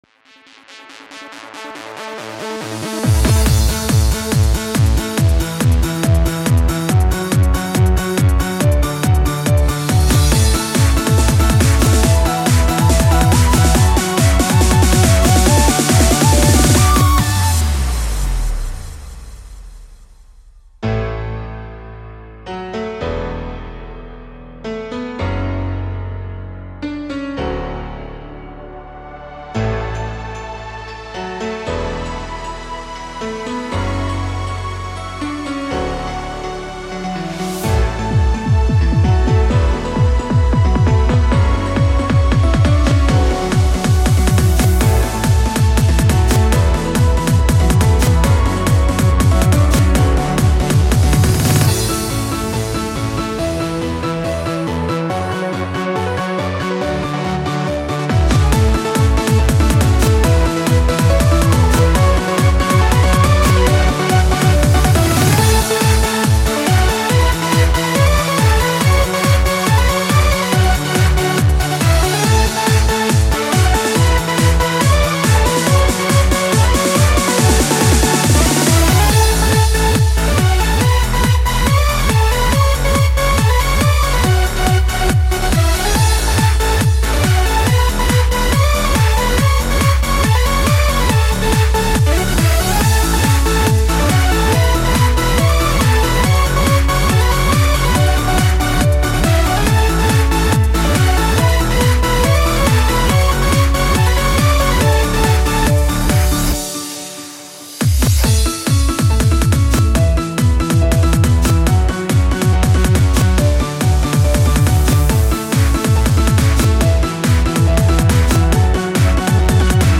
Hooves-Up/Hard Trance (Bootleg) Here it is!
There needed to be a hands-up version.